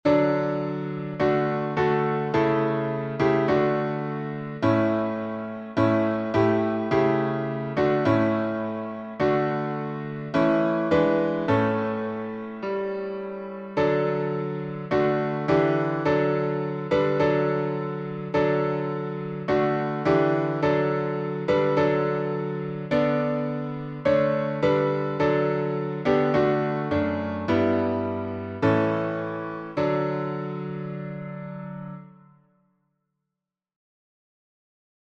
#2046: My Faith Looks Up to Thee — D major | Mobile Hymns